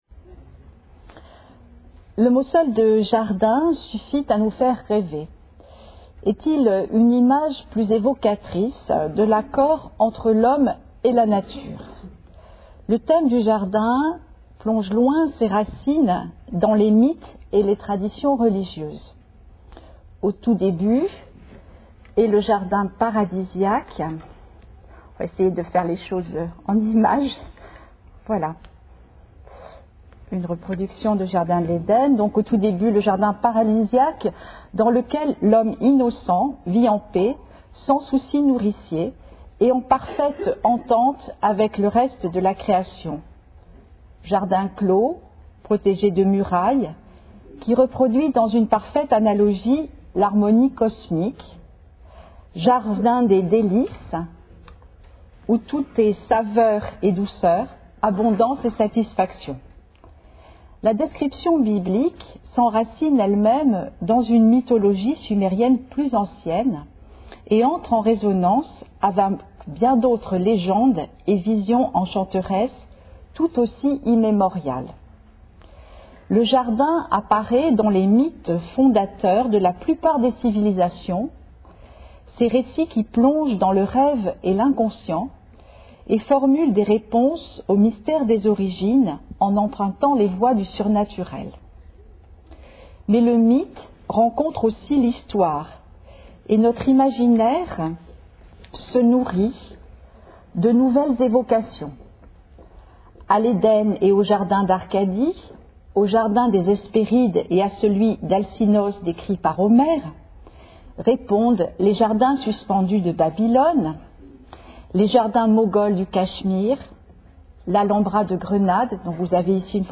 Une conférence de l'UTLS au Lycée L'art des jardins